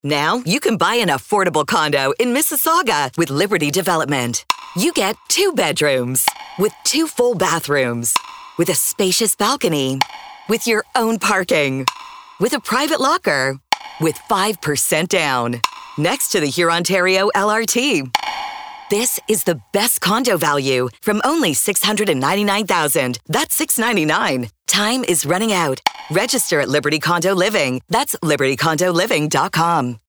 Liberty_Canopy_2_Radio_Commercial.mp3